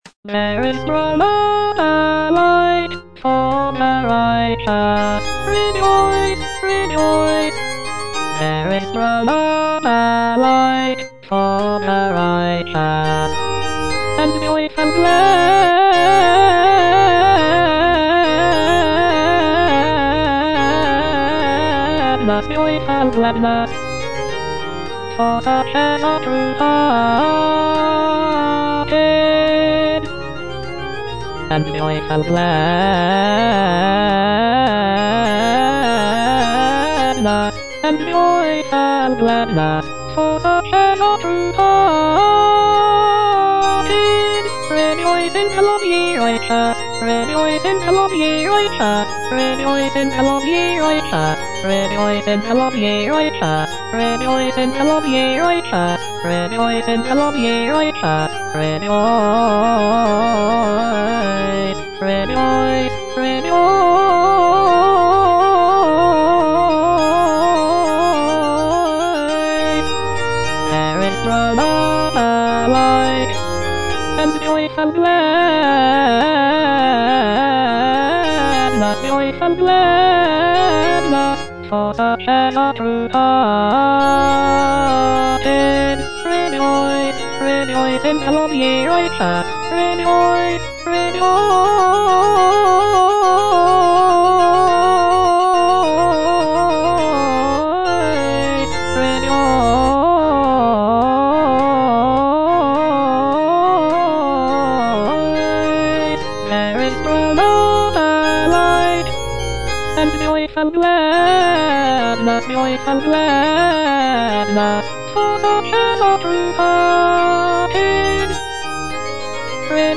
G.F. HÄNDEL - O COME, LET US SING UNTO THE LORD - CHANDOS ANTHEM NO.8 HWV253 (A = 415 Hz) There is sprung up a light - Alto (Voice with metronome) Ads stop: auto-stop
The use of a lower tuning of A=415 Hz gives the music a warmer and more resonant sound compared to the standard tuning of A=440 Hz.